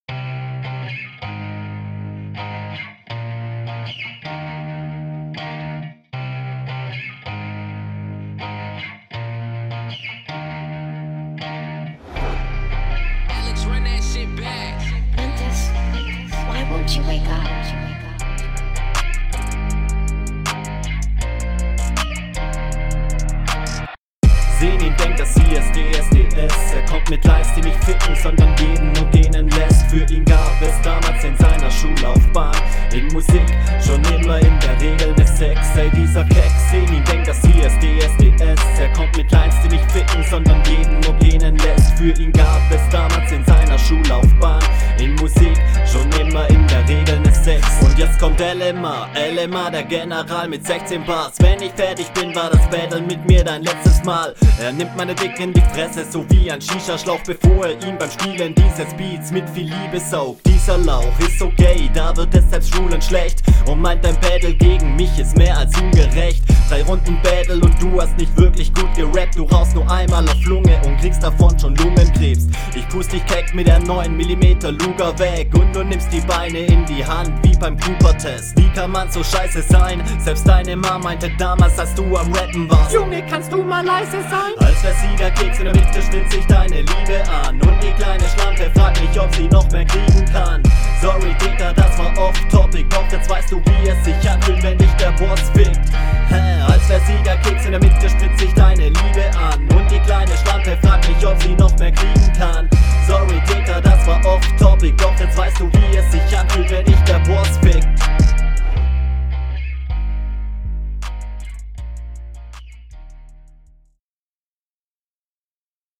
Stimme geht in der Hook am Anfang bisi unter aber danach klingt das eh besser.